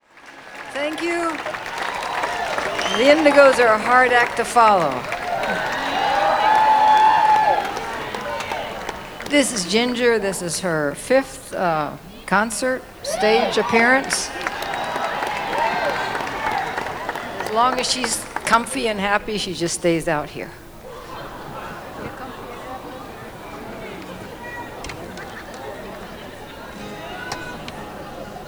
lifeblood: bootlegs: 2014-07-03: the greek theater - los angeles, california (with joan baez)
joan baez set